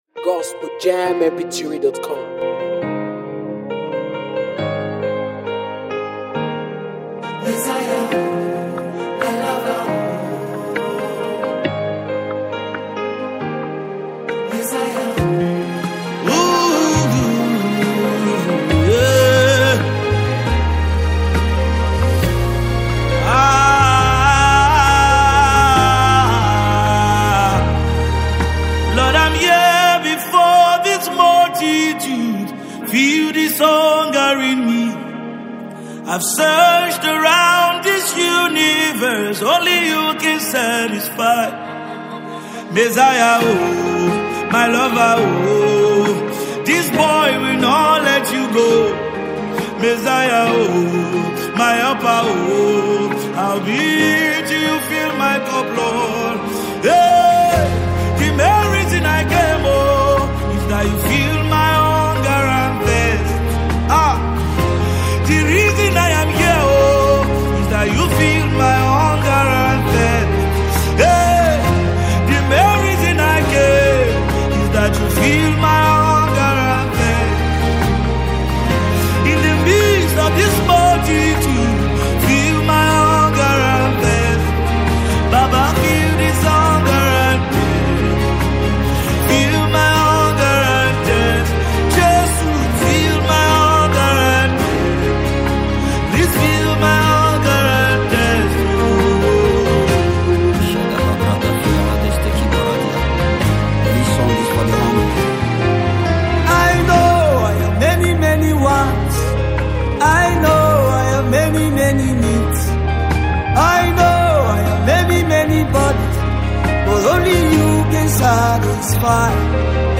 known for worship and contemporary gospel music
his style brings a deep worship vibe.